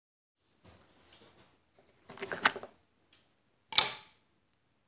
Het klinkt als een soort iets waar je aan draait en dat er vervolgens iets in een bakje valt, maar ik kan me niet eens bedenken wat dat dan zou moeten zijn.
Hint: ik pak iets van een stapeltje en laat het vervolgens ergen op vallen.